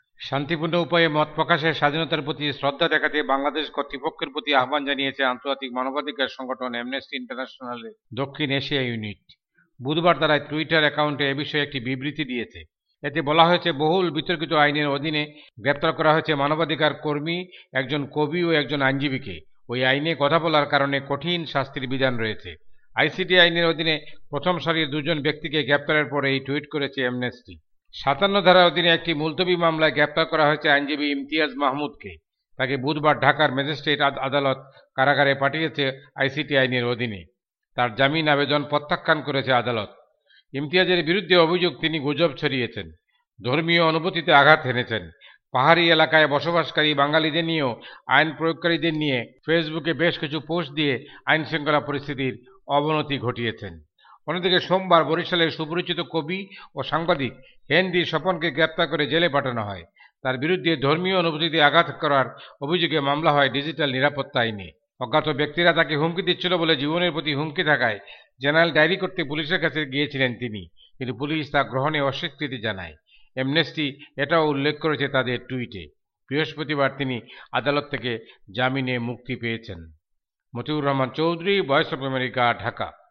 ঢাকা থেকে
রিপোর্ট